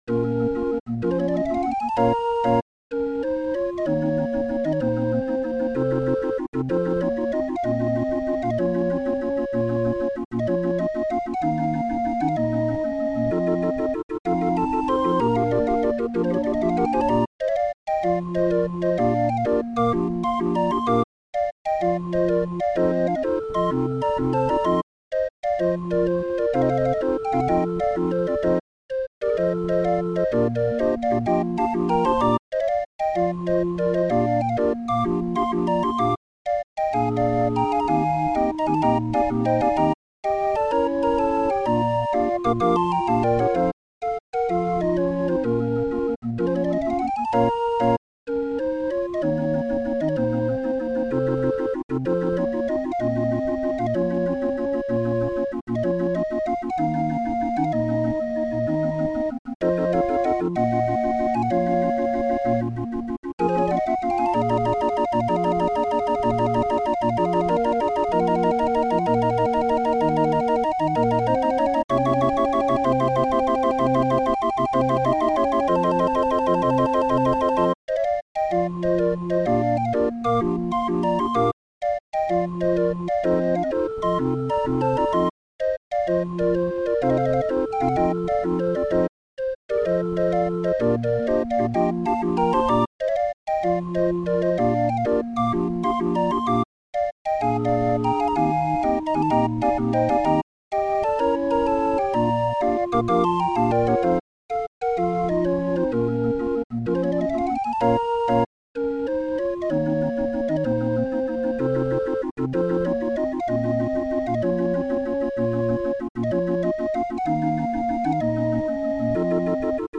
Musikrolle 31-er Raffin